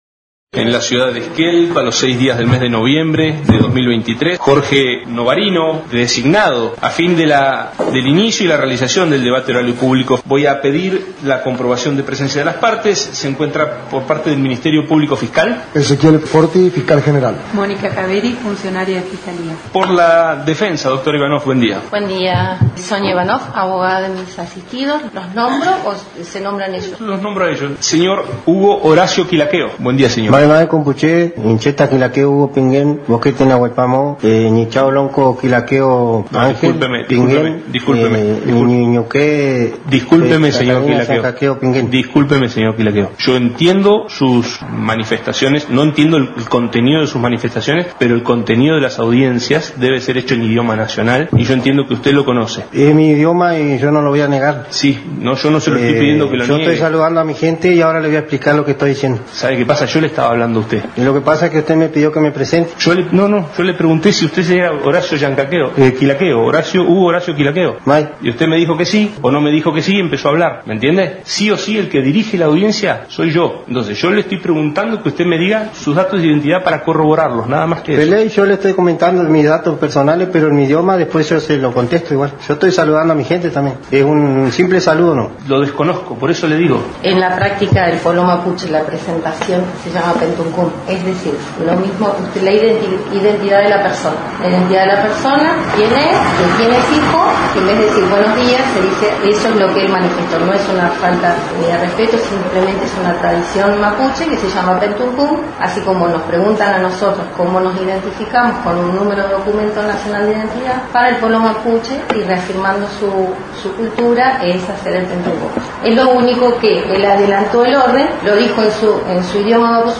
En el marco de una audiencia judicial donde se acusa de usurpación a integrantes de la Lof Nahuelpan, quedó en evidencia la falta de una perspectiva intercultural de parte de un juez integrante del Poder Judicial de Chubut como es el Juez de Esquel Jorge Novarino, quien en una actitud considerada racista y supremacista, no el permitió a un acusado ejercer su cultura ancestral y presentarse según la práctica denominada en la lengua Mapuche como Pentukün, saludo protocolar que constituye toda una ceremonia, considerando valores de respeto a las relaciones sociales con las personas, que se practica desde la niñez hasta la vida adulta. Lo que sucedió fue que Novarino frenó bruscamente la palabra del acusado, para decirle que debía presentarse en el idioma nacional.